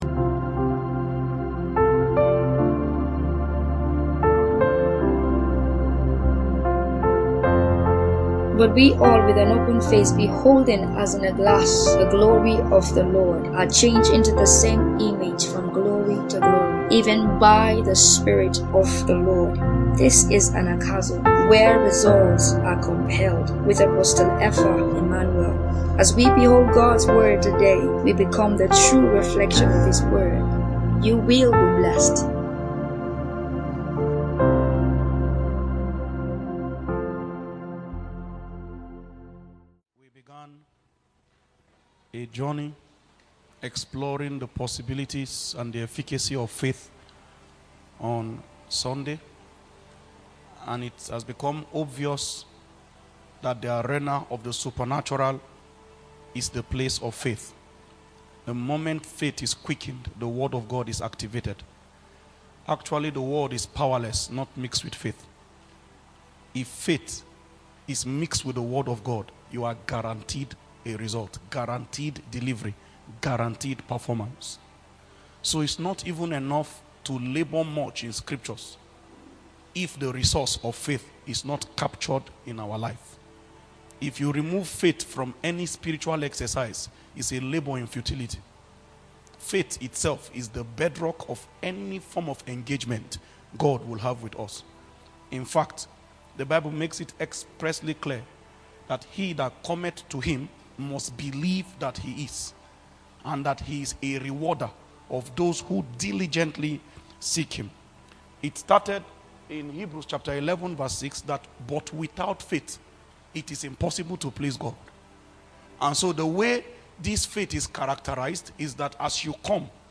Sermon | Anagkazo Mission International | Anagkazo Mission International